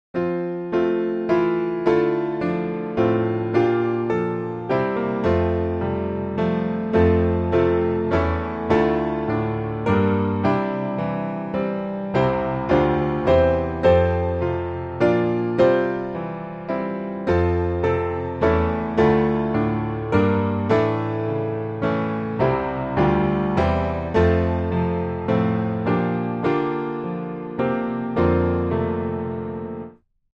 Piano Hymns
F Major